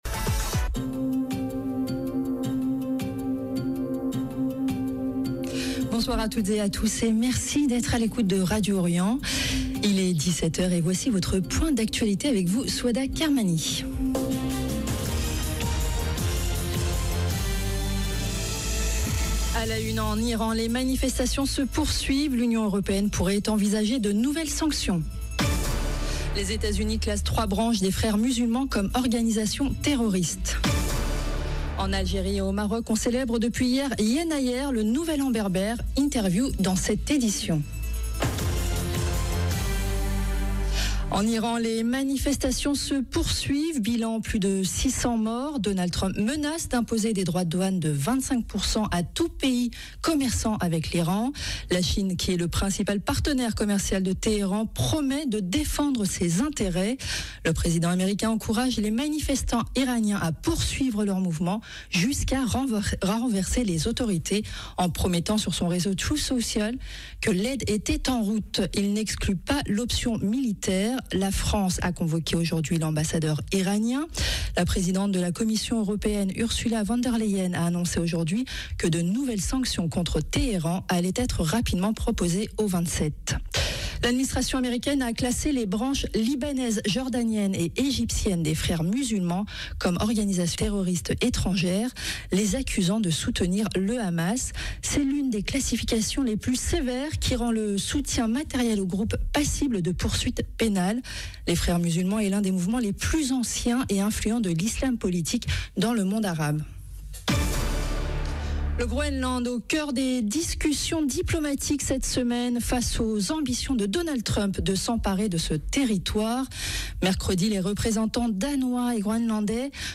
Les États-Unis classent trois branches des Frères musulmans comme organisations terroristes. En Algérie et au Maroc on célèbre depuis hier, Yennayer, le nouvel an berbère. Interview dans cette édition. 0:00 10 min 59 sec